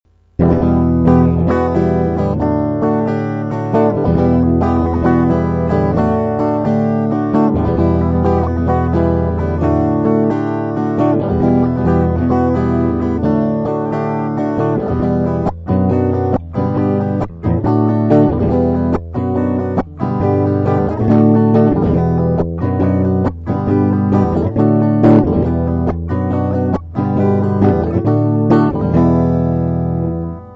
Вступление(Em - D):
Проигрыш (Em - Hm - G - D):
mp3 - вступление и проигрыш